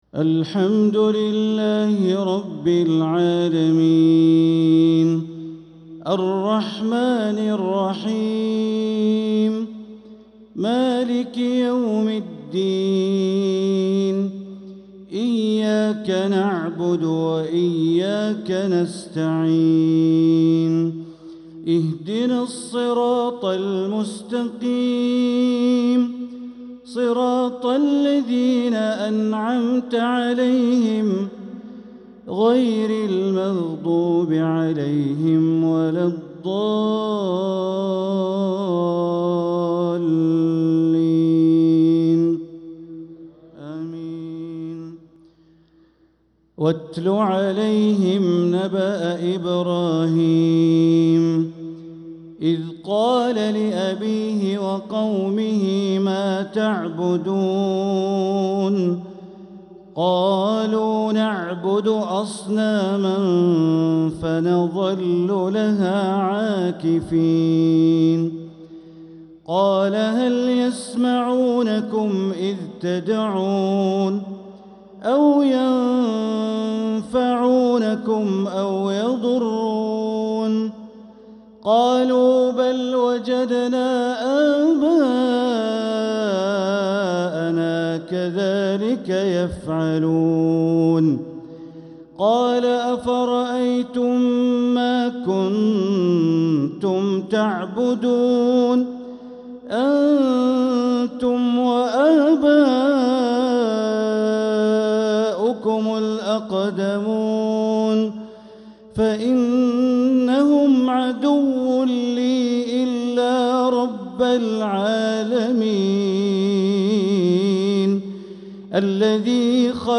Isha prayer from Surat ash-Shuara 3-2-2025 > 1446 > Prayers - Bandar Baleela Recitations